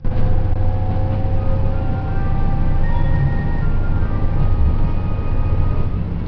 ・KTR700・800形車内チャイム
シンプルながら車内チャイムが３曲用意されています。